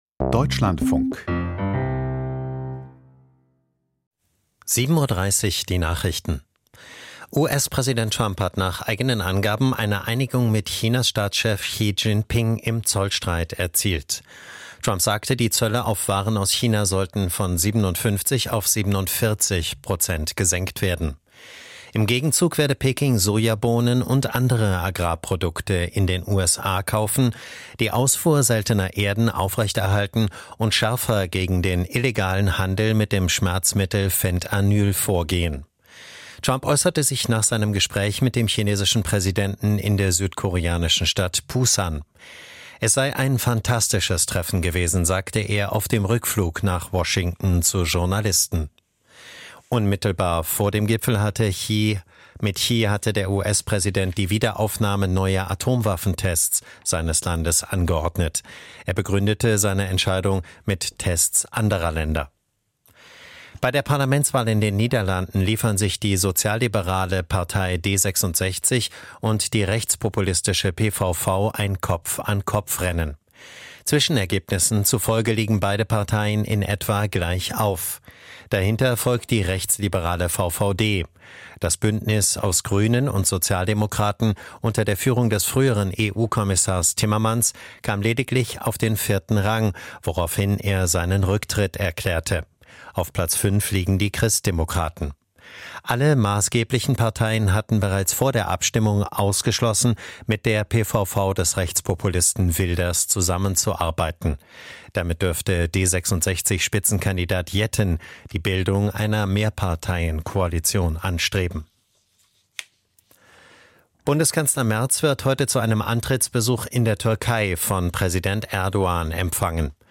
Die Nachrichten vom 30.10.2025, 07:30 Uhr